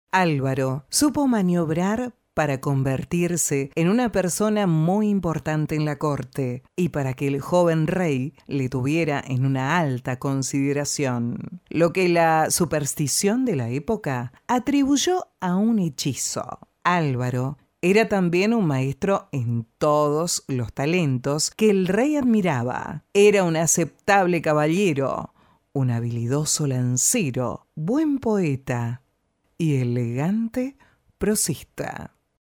Argentinian female voice over
locutora neutra, latin american voice over, american voice talent